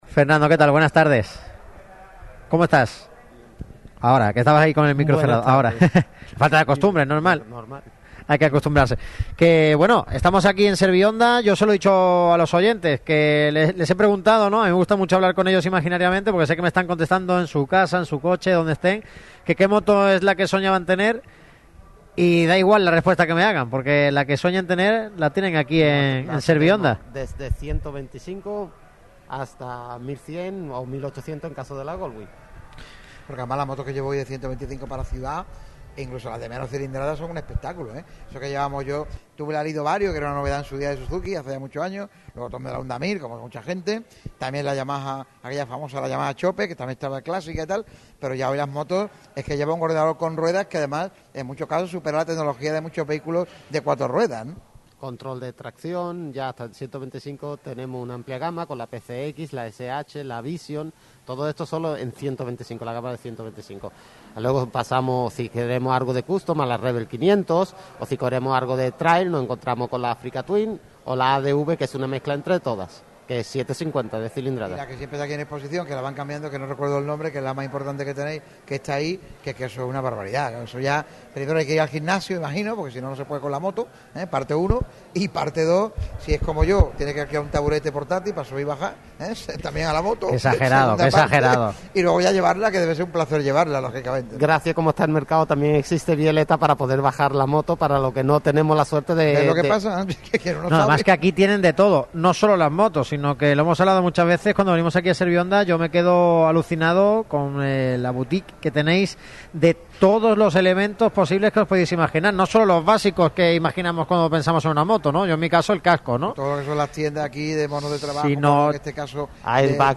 La radio que vive el deporte trasladó su estudio móvil el martes 1 de junio hasta las instalaciones de ServiHonda, el Concesionario Oficial Honda Motos para Málaga y provincia, para realizar su programa diario. En él se analizó toda la actualidad del deporte malagueño y, sobre todo, la del Málaga CF y del Unicaja Málaga.